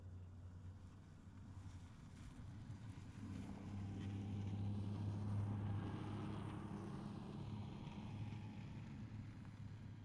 Zero Emission Snowmobile Description Form (PDF)
Zero Emission Subjective Noise Event Audio File (WAV)